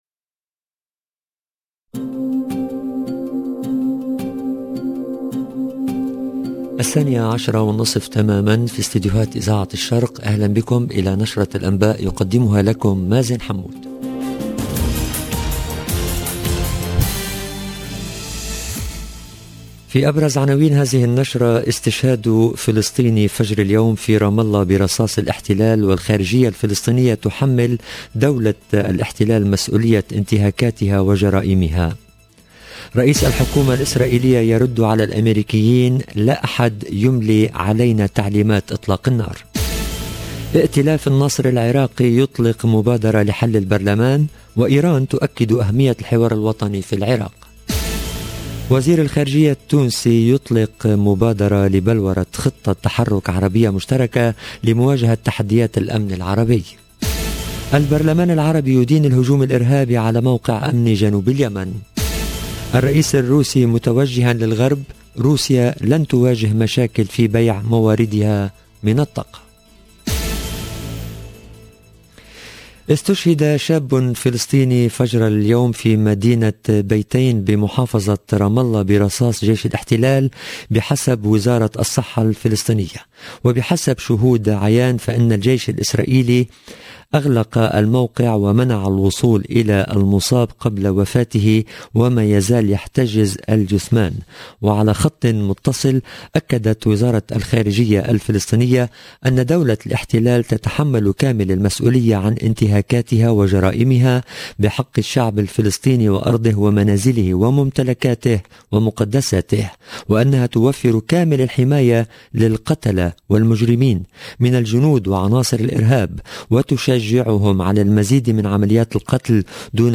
LE JOURNAL EN LANGUE ARABE DE MIDI 30 DU 8/09/22